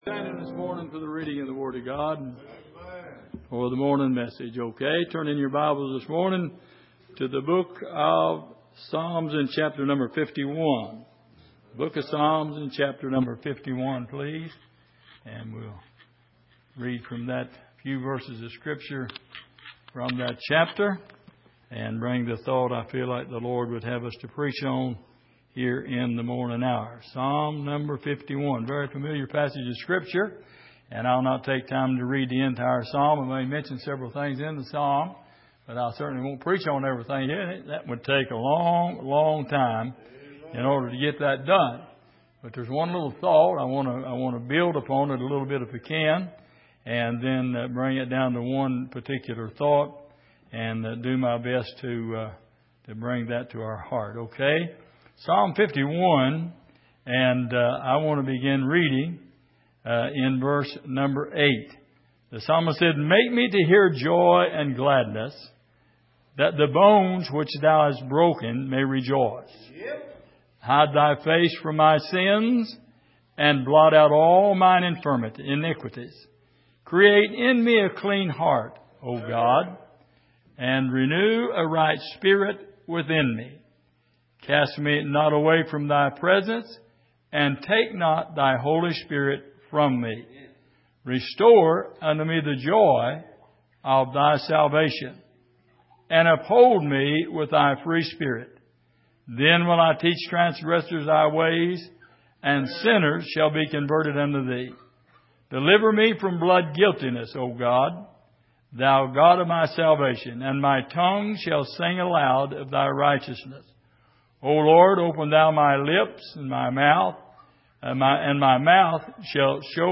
Passage: Psalm 51:12 Service: Sunday Morning